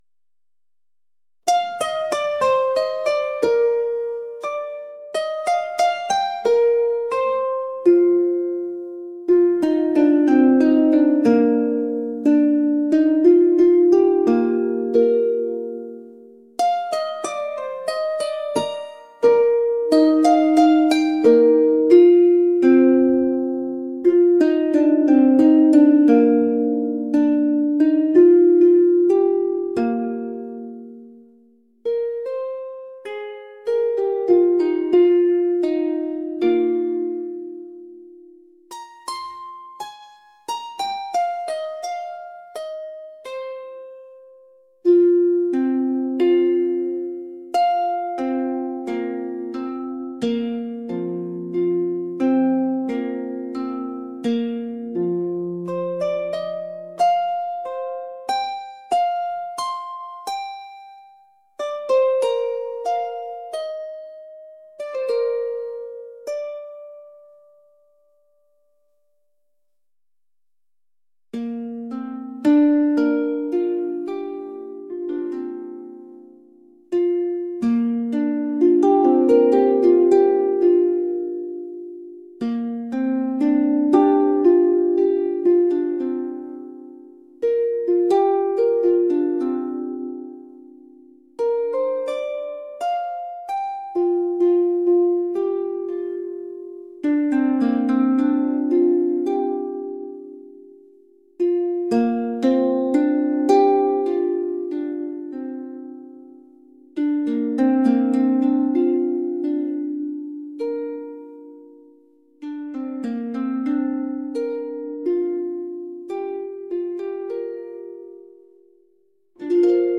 traditional | world